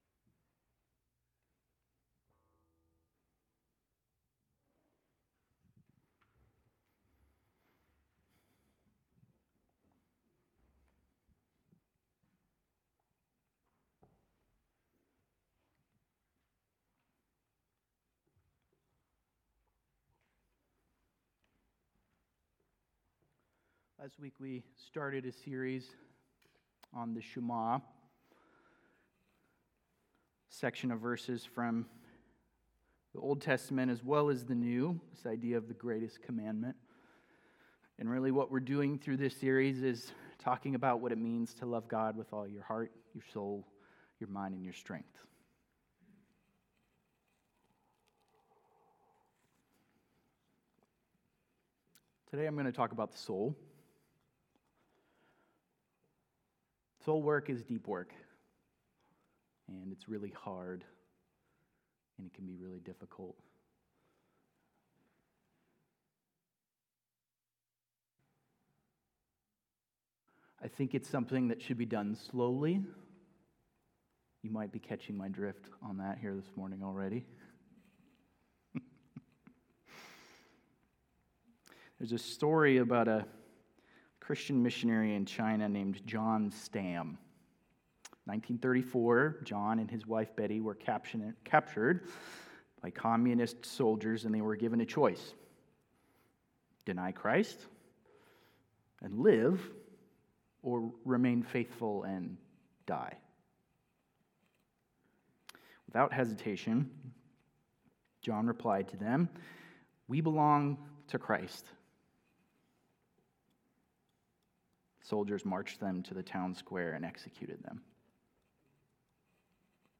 Sermons by First Free Methodist Spokane